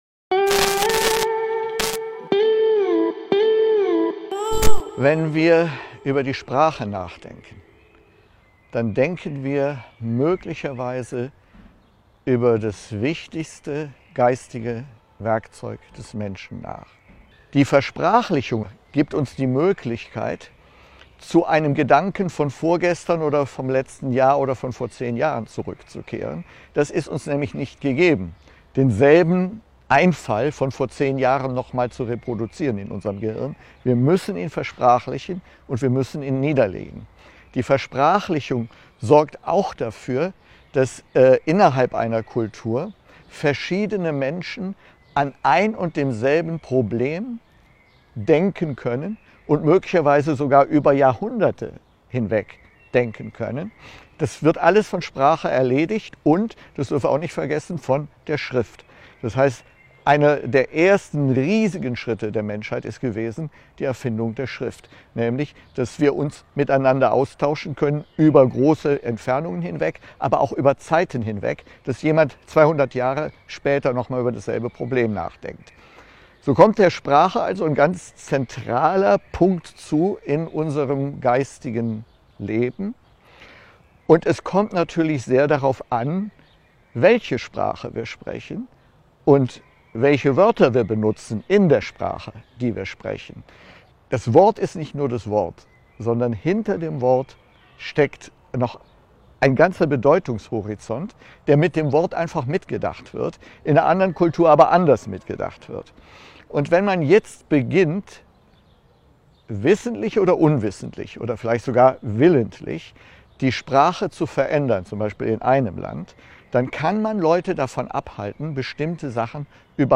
Wichtig finde ich in diesem Vortrag u.a. die Betonung auf Sprache als Transporteur von eigener Kultur, Tradition und Geisteshaltung.